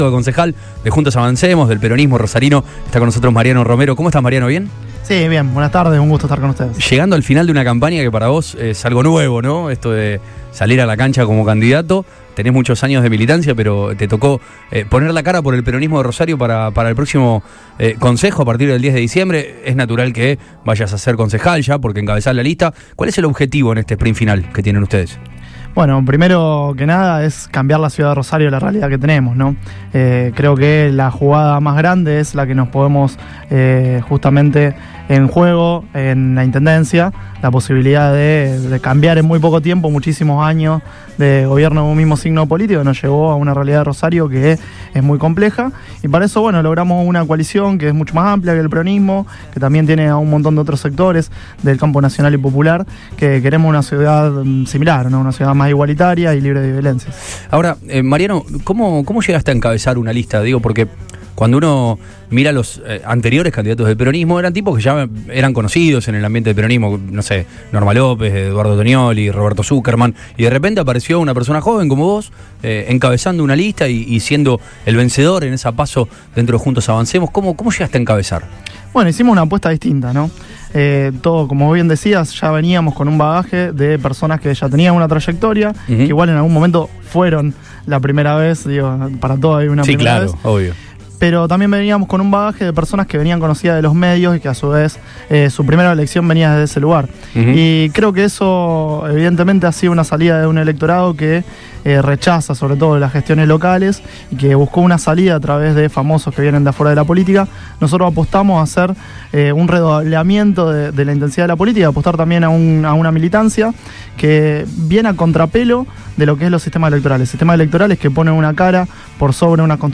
pasó por los estudios de Radio Boing.
entrevista radial